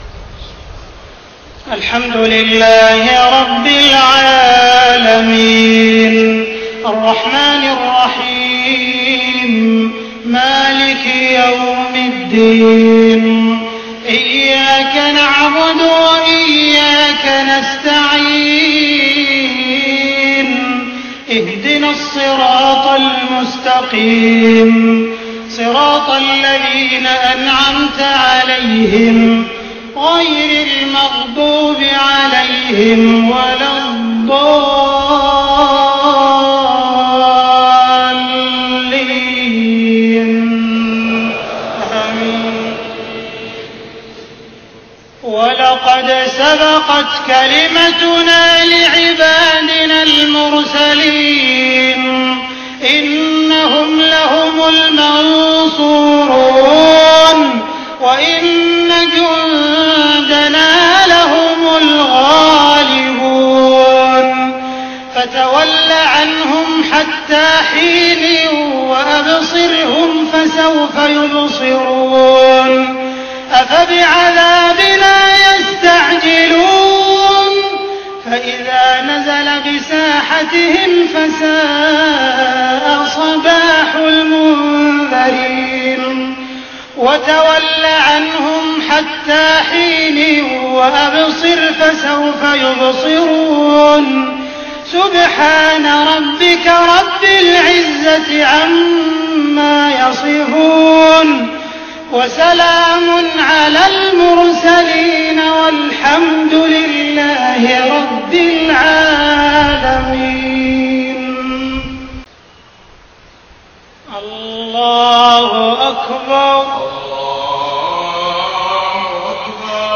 صلاة الجمعة 5 محرم 1430هـ خواتيم سورة الصافات 171-182 و النصر > 1430 🕋 > الفروض - تلاوات الحرمين